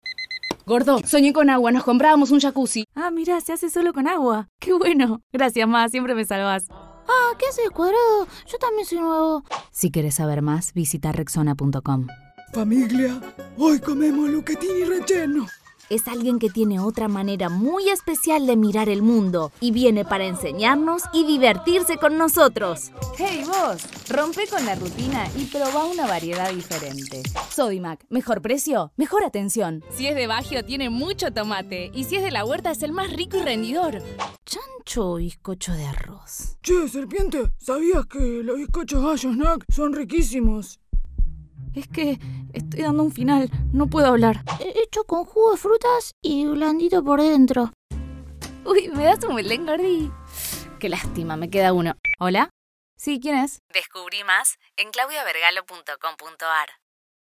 Voz dúctil, alegre, cálida, amable y fresca.
Sprechprobe: Werbung (Muttersprache):
Ductile voice, cheerful, warm, friendly and fresh.